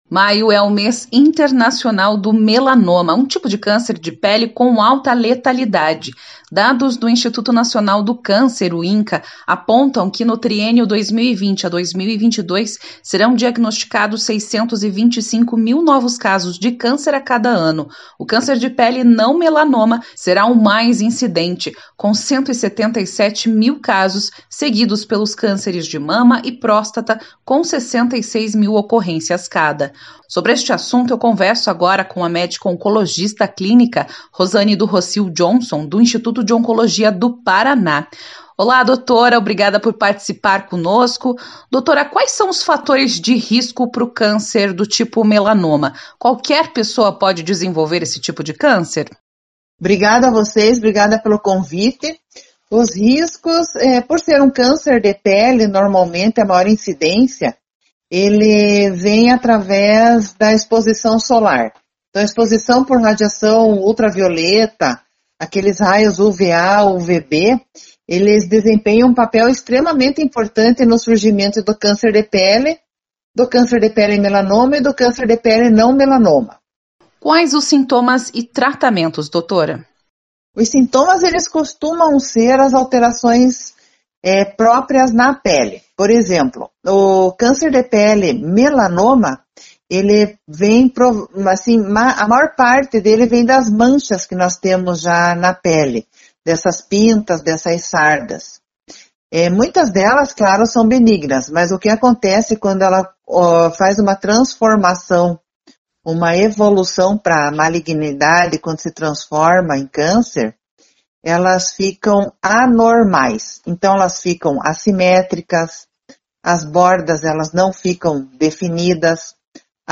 ENTREVISTA: Maio traz alerta para os perigos do câncer de pele